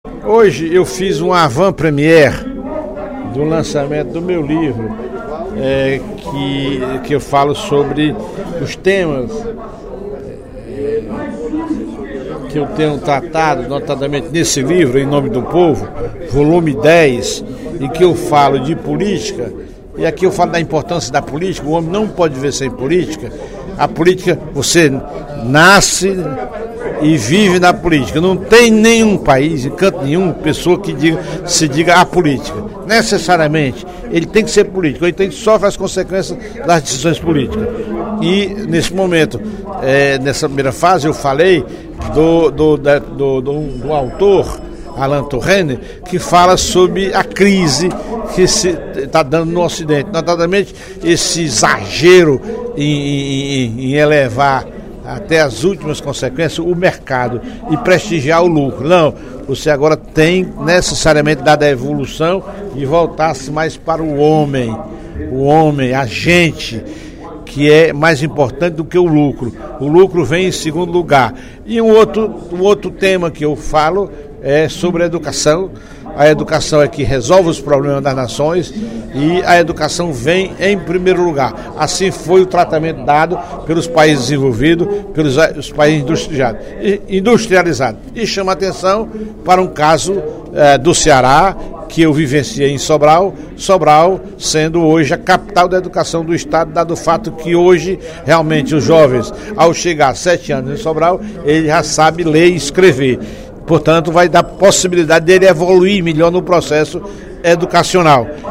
No primeiro expediente da sessão plenária desta quarta-feira (12/06), o deputado Professor Teodoro (PSD) apresentou o livro Em Nome do Povo, de sua autoria, que traz reflexões sobre política, cidadania, educação e religiosidade.
Em aparte, a deputada Inês Arruda (PMDB) elogiou o deputado Professor Teodoro pela “lucidez quando fala de educação e cultura nesta Casa”.